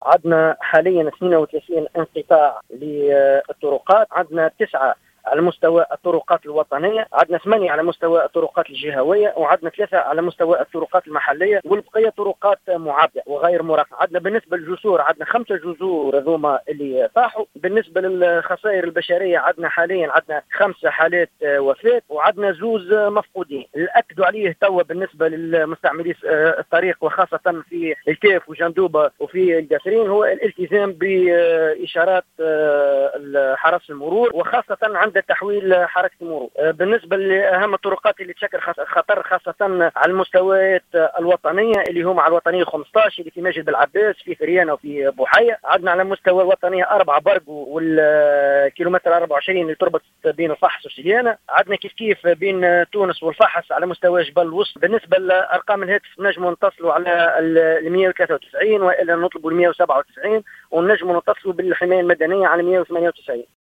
أكّد العقيد حسام الدين الجبابلي الناطق الرسمي باسم الحرس الوطني، في تصريح للجوهرة اف ام اليوم الخميس 18 أكتوبر 2018، أنه تم تسجيل 5 وفيات ومفقودين اثنين جراء الأمطار.